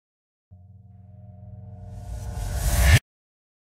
Звуки операции
Операция и шепот хирургов